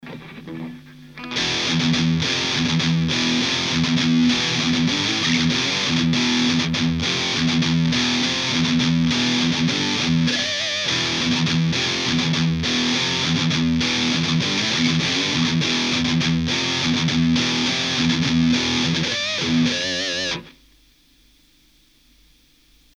j'ai fais un sample pour le son en palm mute.
J'ai un micro pas top pour enregistrer (il bouffe pas mal les aigus .... pis les graves aussi ). du coup j'ai essayer d'enregistrer mon bleme dans les aigus ... mais ça s'entend pas a l'enregistrement
Et la l'EQ de l'ampli est a midi.
Par contre sur l'enregistrement que j'ai posté c'est ce que je disais : les graves sont assez presents, du coup ce son aigu qui me gene me derange beaucoup moins (pis en plus avec mon micro de toute façon il passe pas ).